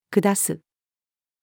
下す-female.mp3